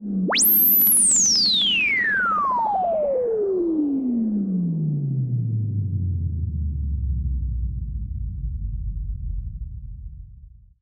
Roland E Noise 09.wav